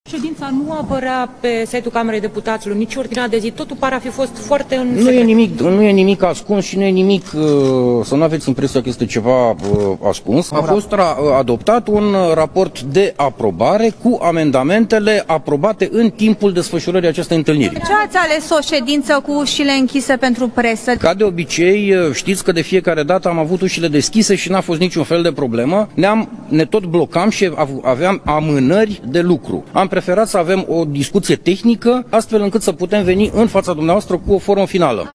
Președintele Comisiei de Sănătate din Camera Deputaților, Corneliu Florin Buicu, a fost întrebat de jurnaliști de ce la ședință nu a fost permis accesul presei: